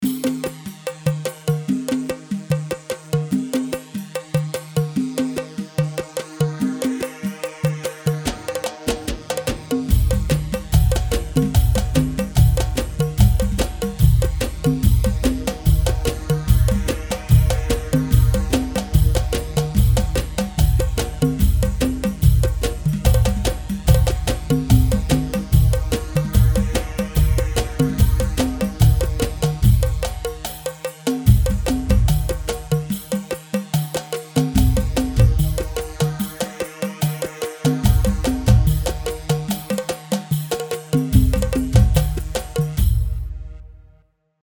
Khaleeji